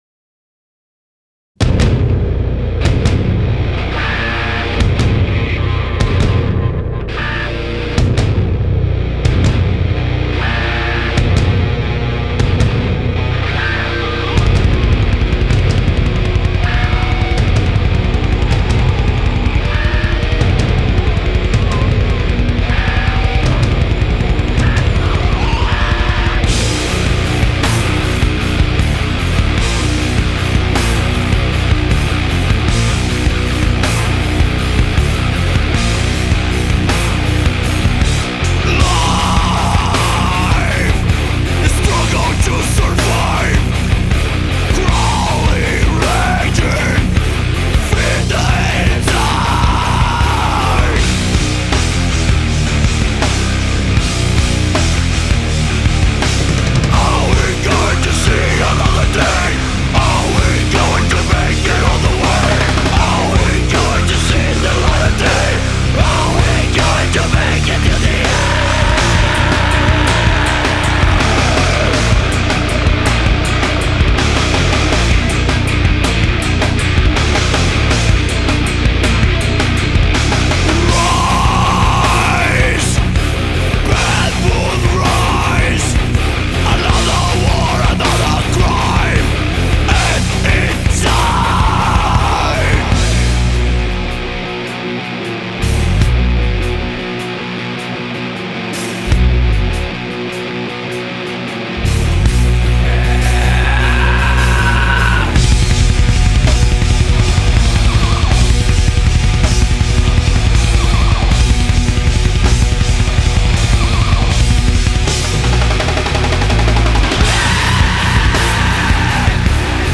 Жанр: Groove metal, nu metal, Thrash, heavy metal